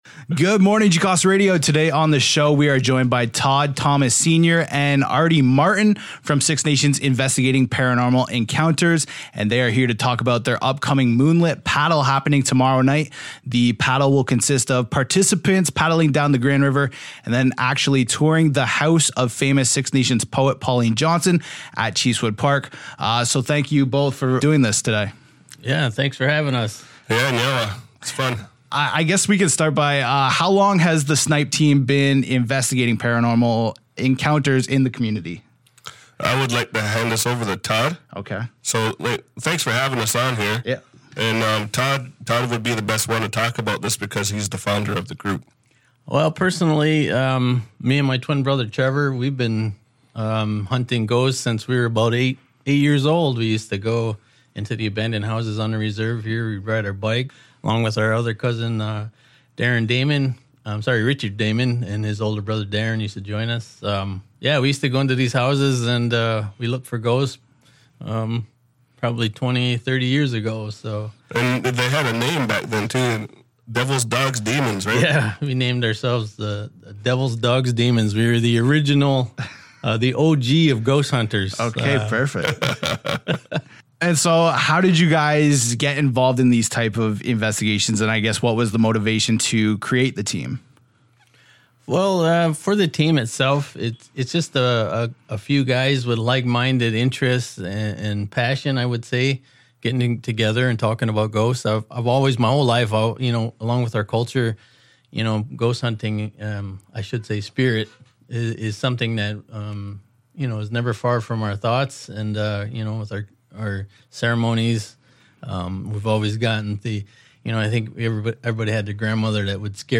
full-interview-edit_01.mp3